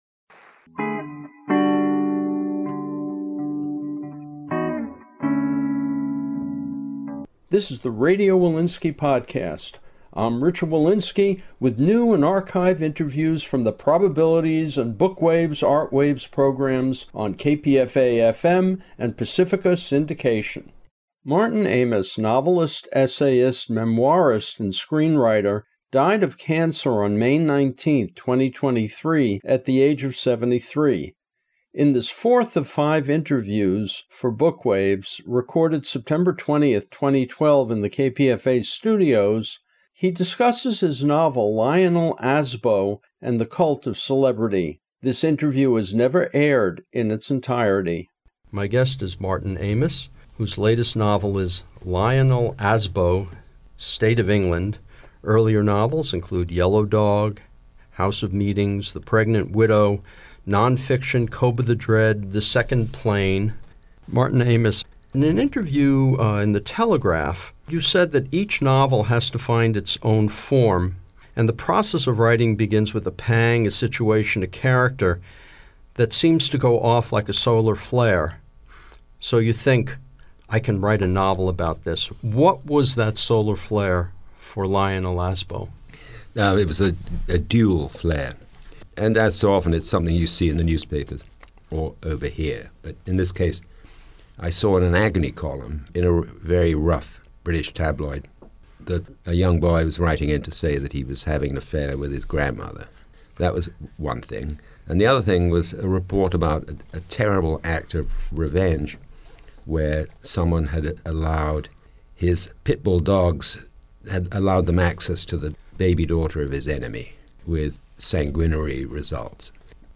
Martin Amis (1949-2023), in conversation